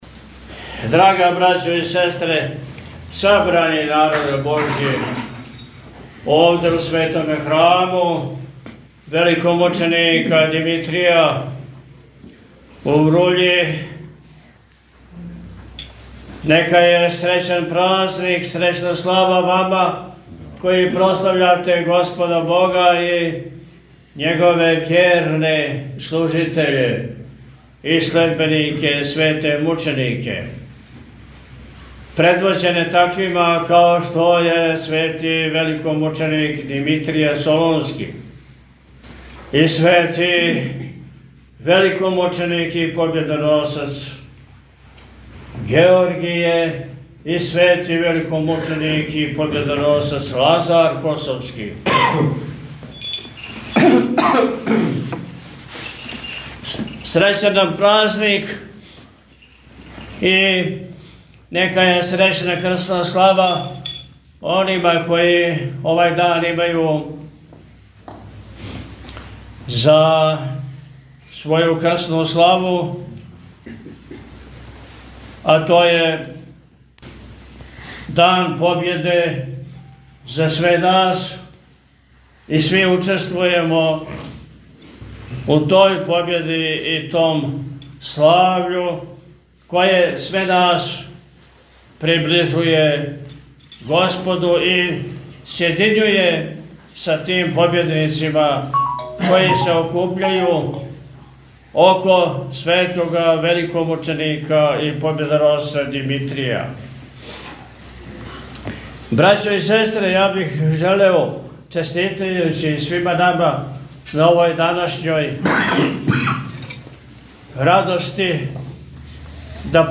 Поводом прославе храмовне славе Његово Високопреосвештенство Архиепископ и Митрополит милешевски г. Атанасије служио је, у петак 8. новембра 2024. године на дан када Српска Православна [...]
Честитајући празник и славу храма сабраном верном народу Високопреосвећени Епископ Атанасије је у пастирској беседи, поред осталог, рекао: – Ово је дан победе за све нас, и сви учествујемо у тој победи и том слављу које све нас приближује Господу и сједињује са тим победницима који се окупљају око Светог великомученика и победоносца Димитрија.